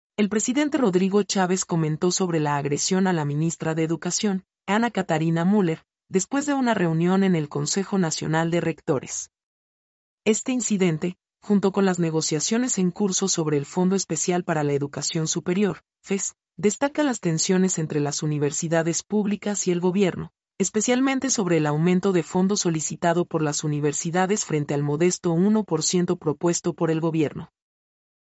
mp3-output-ttsfreedotcom-30-1.mp3